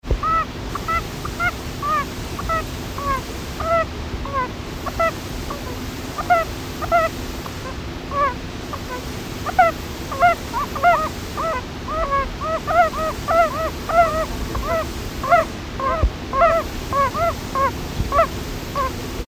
Audiodateien, nicht aus dem Schutzgebiet
Gelbbauchunke meckert UB